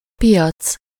Ääntäminen
Synonyymit attraction excrément bazar Ääntäminen Tuntematon aksentti: IPA: /fwaʁ/ IPA: /fwɑʁ/ Haettu sana löytyi näillä lähdekielillä: ranska Käännös Ääninäyte Substantiivit 1. bazár Muut/tuntemattomat 2. piac Suku: f .